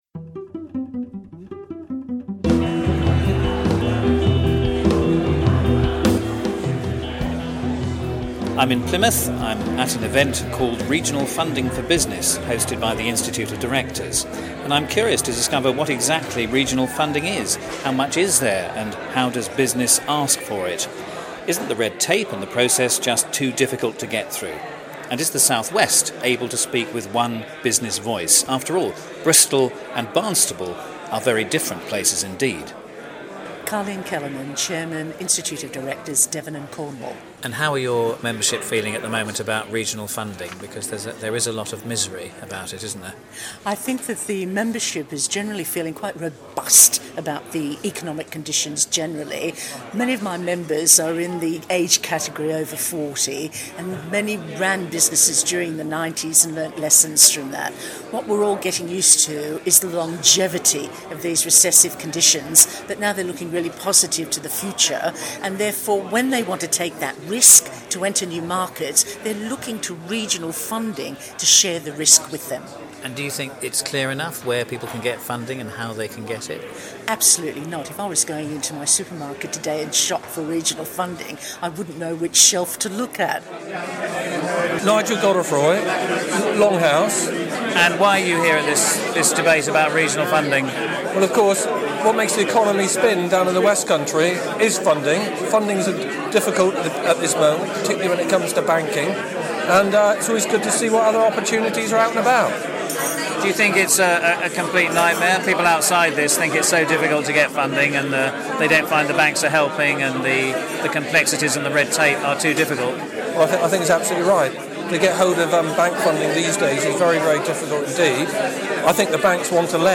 getting the views of business people at an event in Plymouth. How much do they know about what public funds are available for business growth and how to access them?